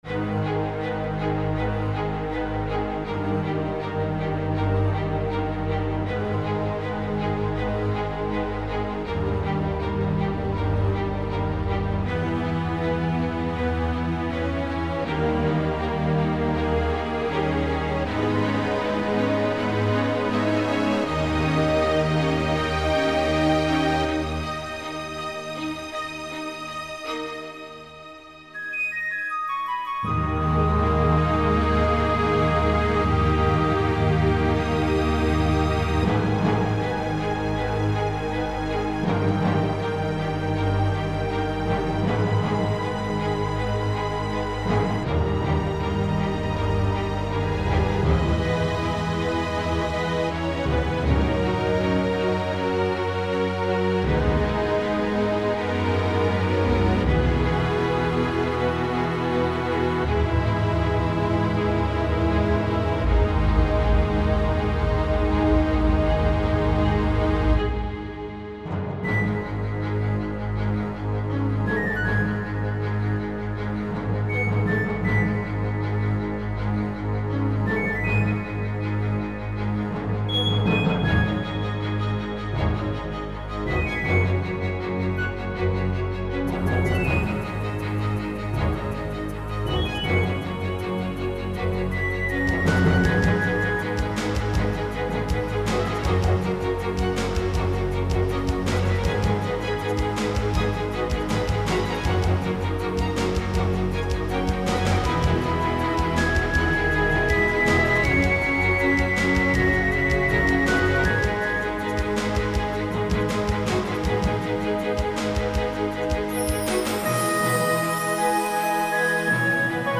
These are links to some sample pieces that I have composed using soundfonts and samples.
Night of the Calling - quasi-symphonic short theme.